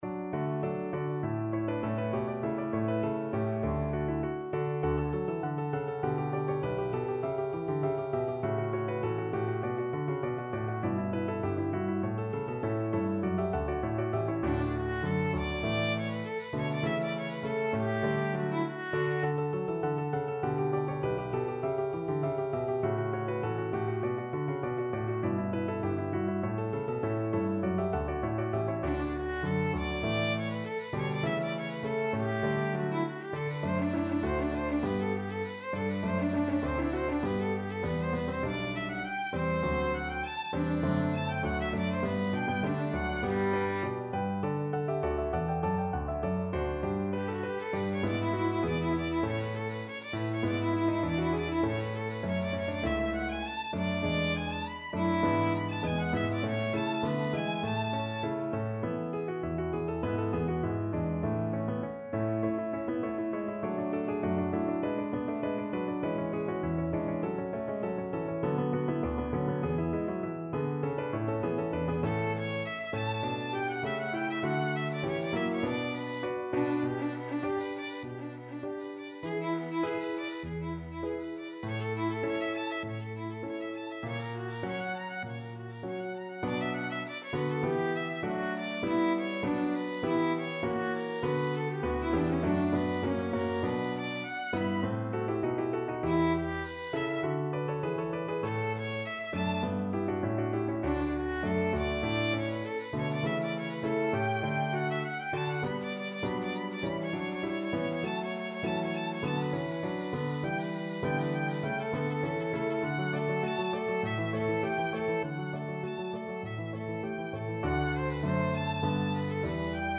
4/4 (View more 4/4 Music)
Allegro (View more music marked Allegro)
Classical (View more Classical Violin Music)